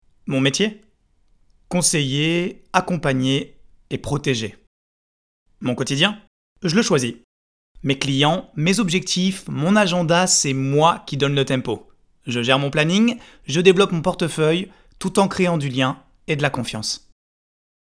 Voix off
10 - 35 ans - Contre-ténor